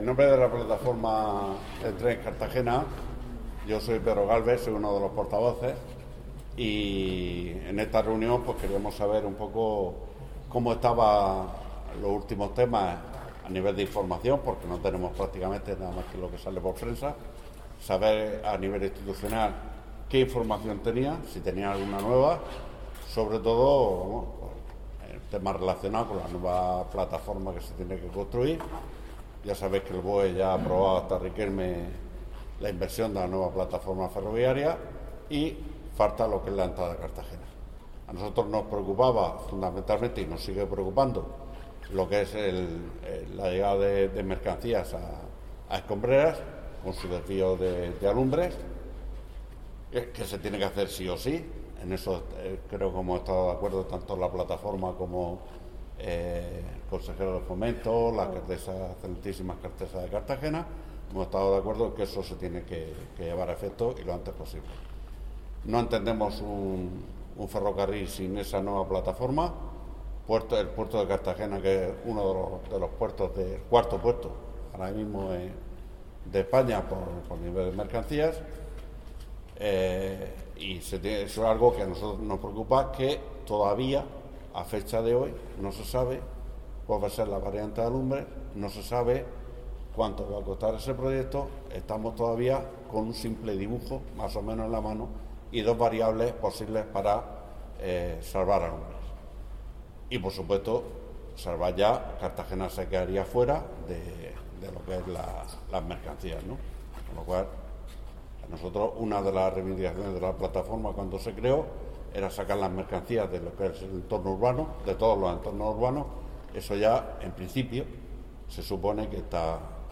Declaraciones de Noelia Arroyo